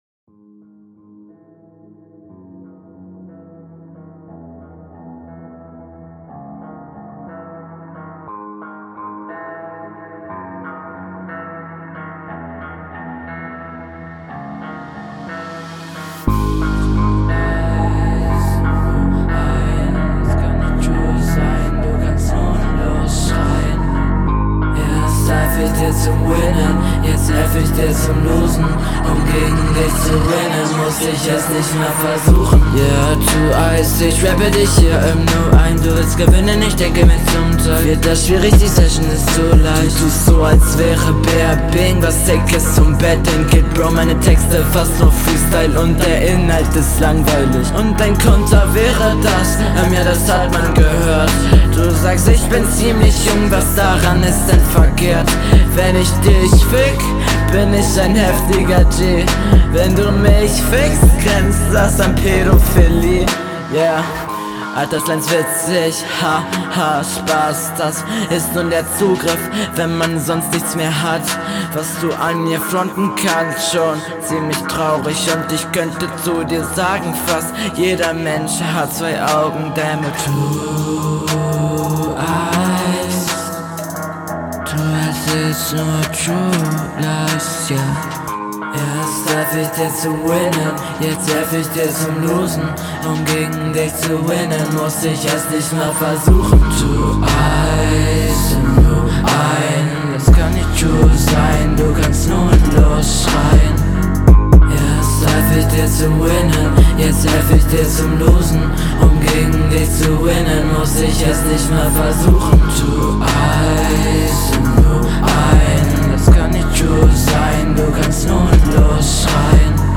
Lil Peep Type Beat wieder.
Yesyoa, soundtechnisch ist das mal wieder supercool, Halftime in den Parts geht auch sehr klar.
Soundqualität: Schöne Mische, gute Effekte.